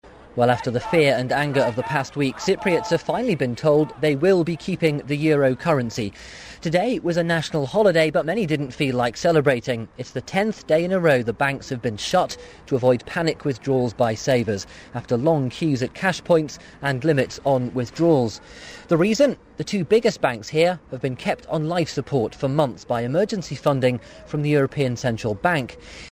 【英音模仿秀】国小债台高 劫富纾困扰 听力文件下载—在线英语听力室